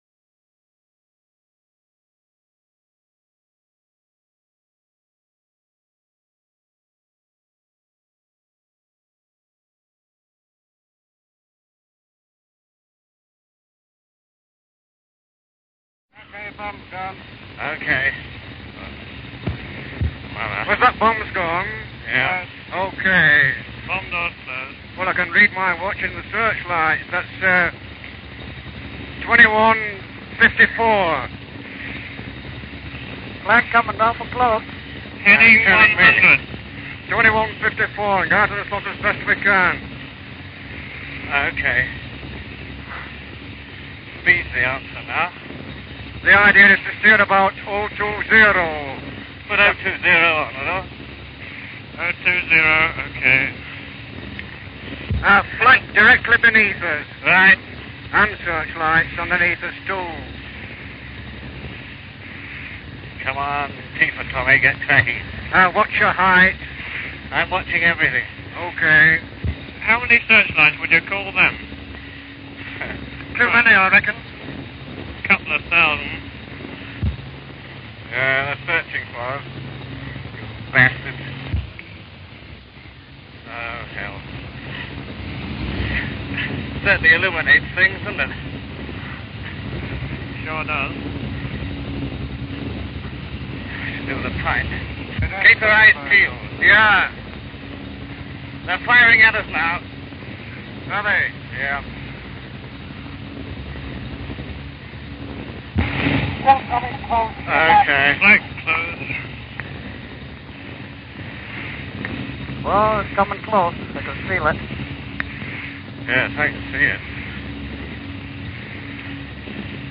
RAF Wartime Radio
Audio from Bombing Raid